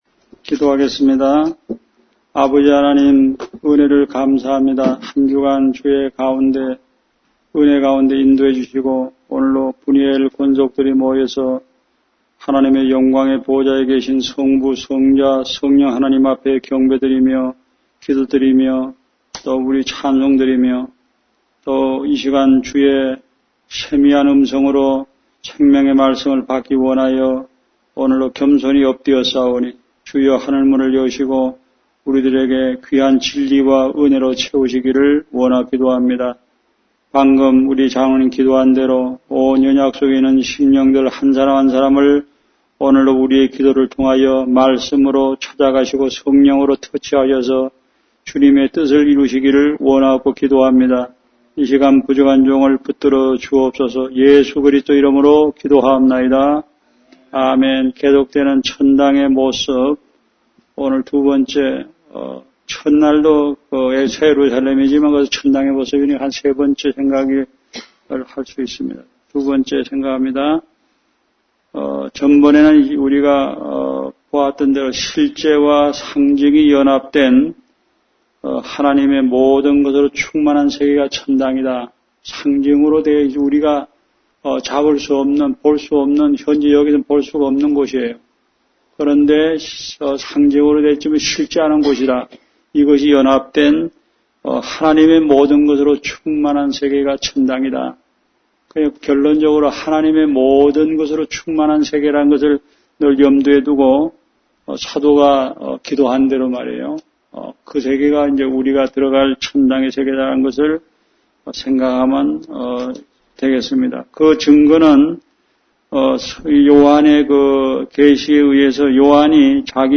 Series: 주일설교